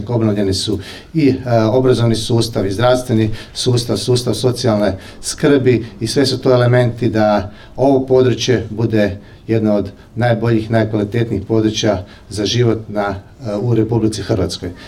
Zamjenik župana Mihael Jurić zahvalio je Vladi RH na odličnoj suradnji, a novim korisnicima poželio ugodan boravak u novim stanovima, ali i da se što prije vrate u svoje obnovljene domove.